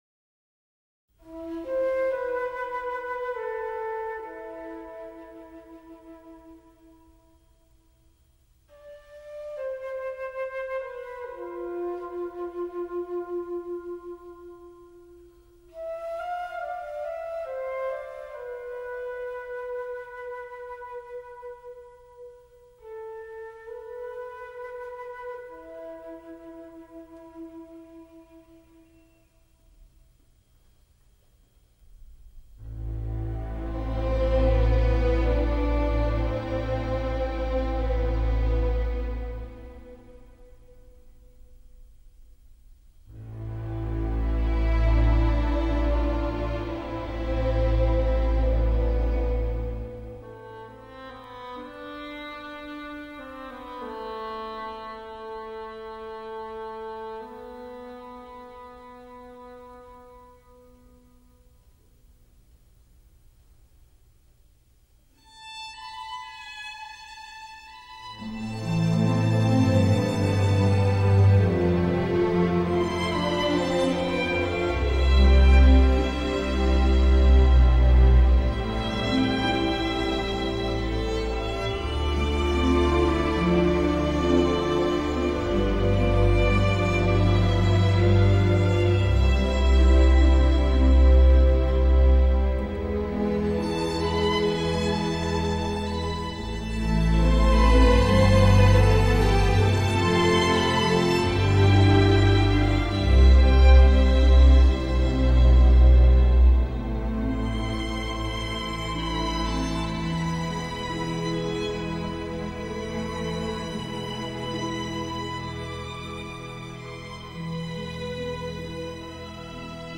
◆ 音乐类别:电影原声
在长笛独白后，拉出管弦乐场景。
两个主题（也是贯串本片的中心主题）呈示过后，又再相错出现发展。